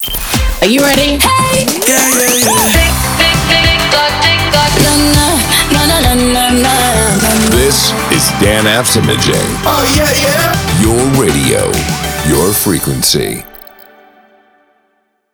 Danaps Imaging Jingles
Jingle-1-Rework-v2.wav